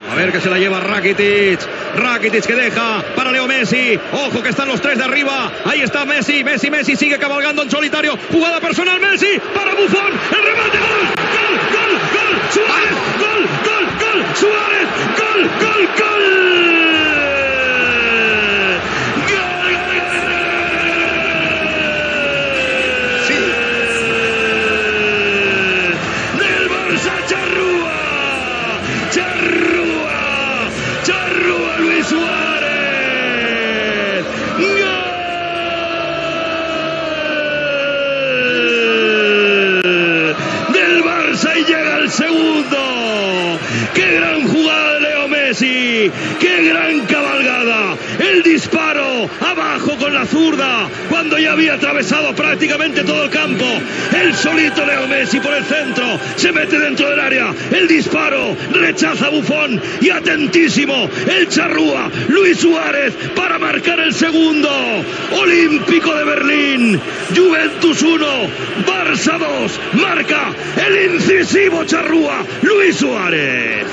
Transmissió de la final de la Copa d'Europa de futbol masculí, des de l'Olympiastadion de Berlín, del partit entre el Futbol Club Barcelona i la Juventus.
Narració del gol de Luis Suárez.
Esportiu